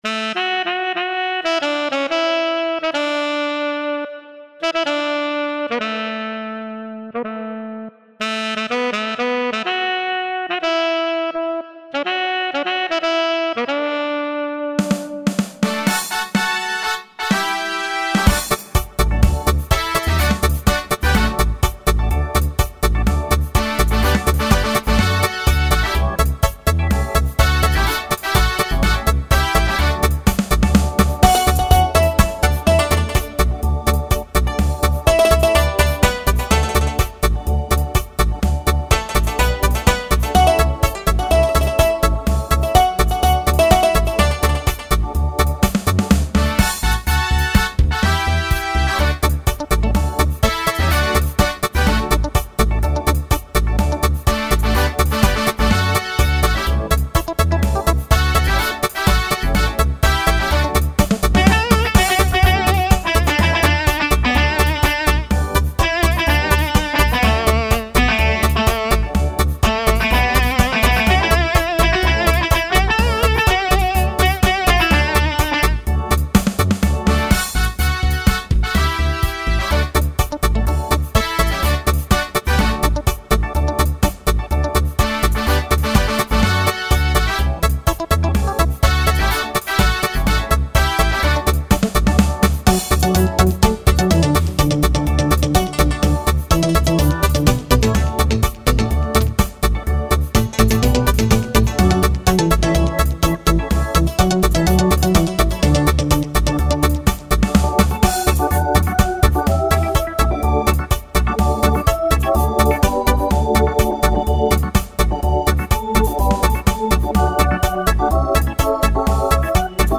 Ska version of a traditional US-American country blues
Ska, Bluebeat
Ska (Jamaica)